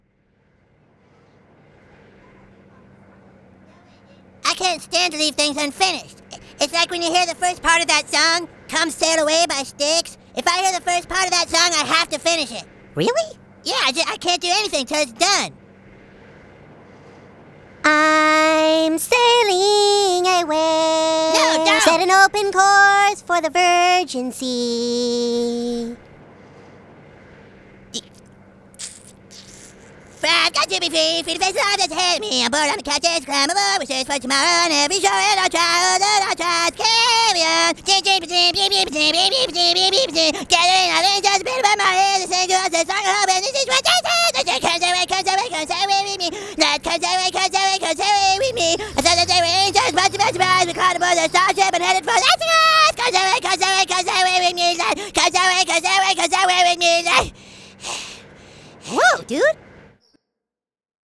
Audio QualityCut From Video